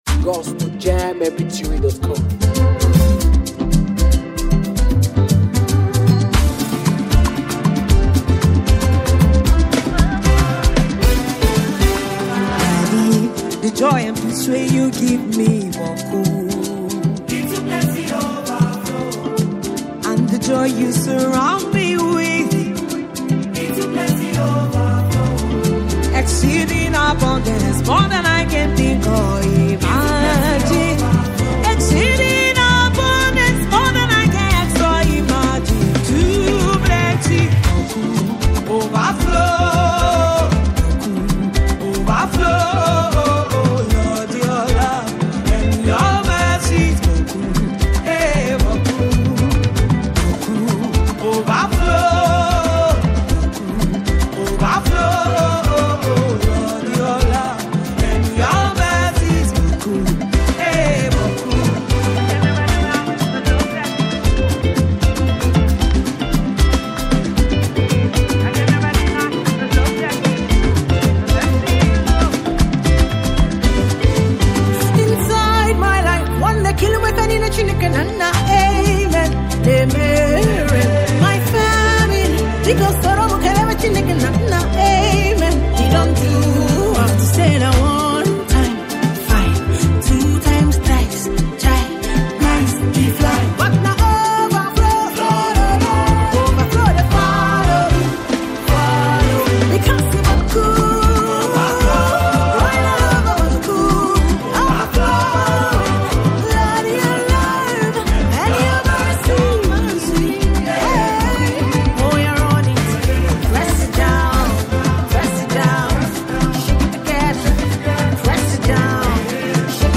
Afro beatmusic
contemporary African gospel worship song